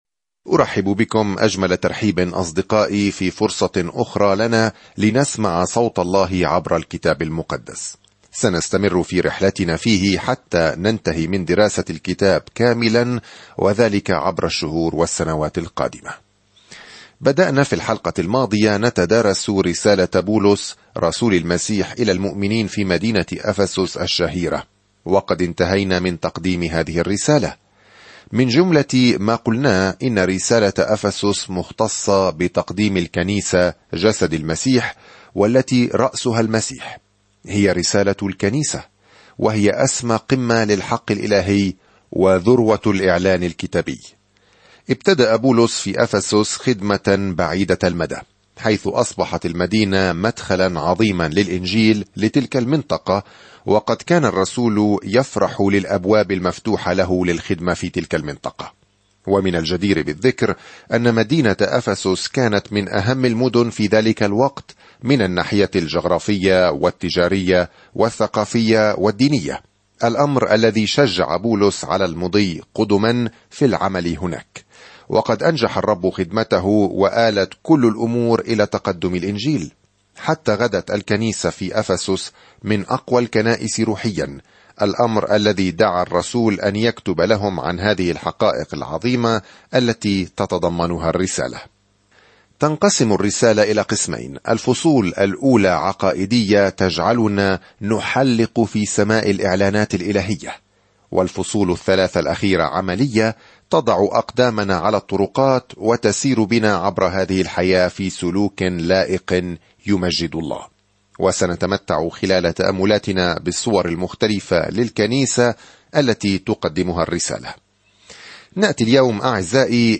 الكلمة أَفَسُسَ 1:1-2 يوم 1 ابدأ هذه الخطة يوم 3 عن هذه الخطة من الأعالي الجميلة لما يريده الله لأولاده، تشرح الرسالة إلى أهل أفسس كيفية السلوك في نعمة الله وسلامه ومحبته. سافر يوميًا عبر رسالة أفسس وأنت تستمع إلى الدراسة الصوتية وتقرأ آيات مختارة من كلمة الله.